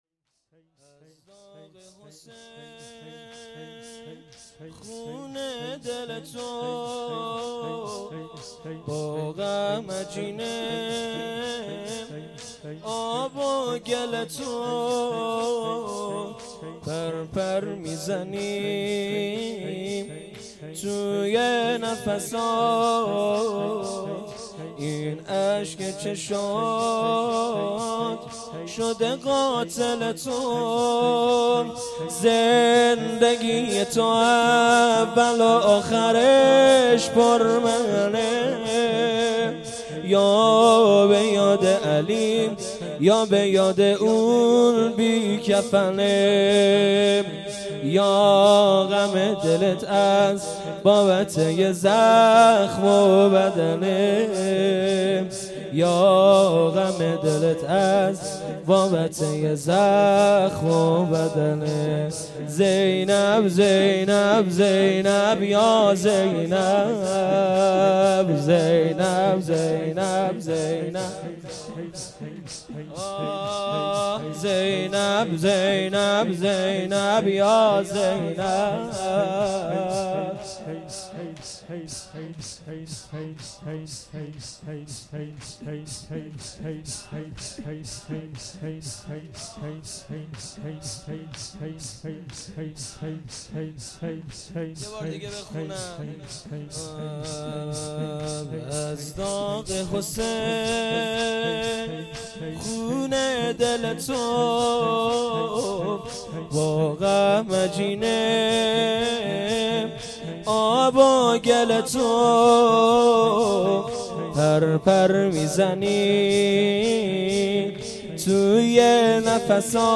جلسه هفتگی ۲۸ شهریور ۱۳۹۸